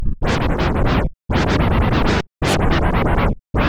На этой странице собраны различные звуки глитч-эффектов — от резких цифровых помех до плавных искажений.
Виниловый глитч эффект